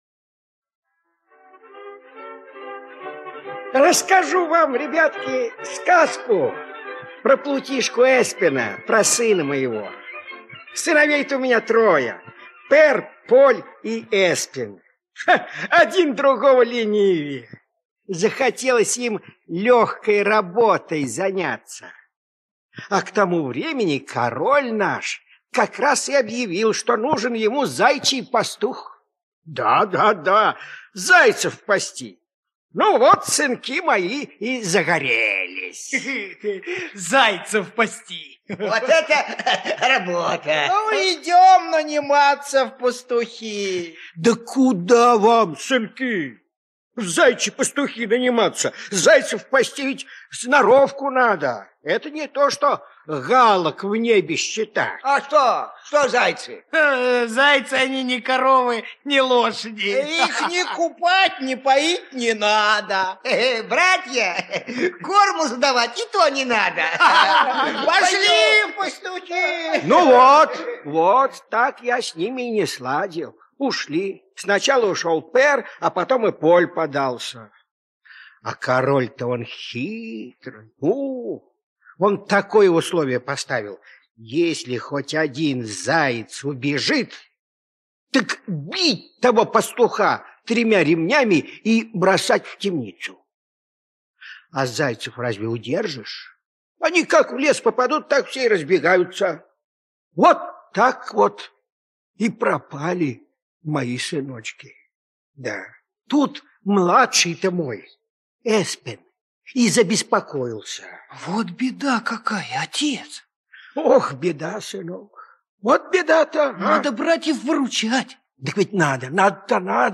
Королевские зайцы - аудиосказка Асбьернсен - слушать онлайн